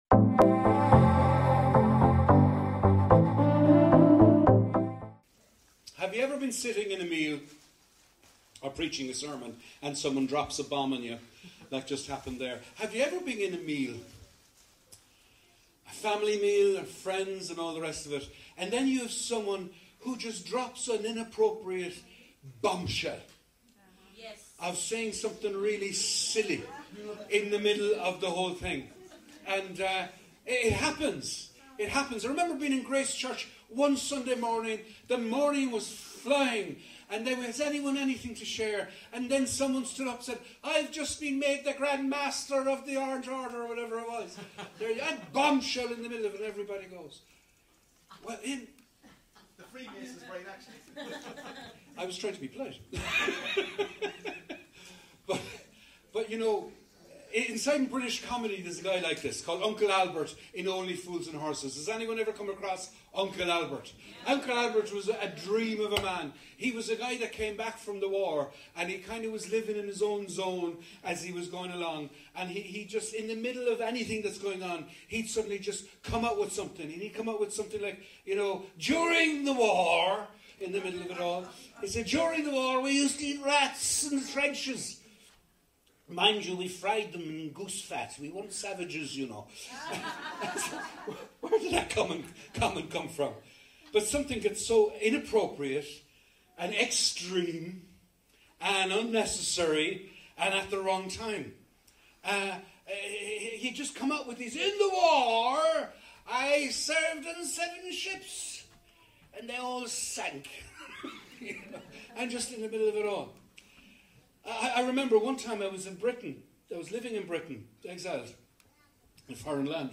How Can We Live In A Crazy World? - Guest Speaker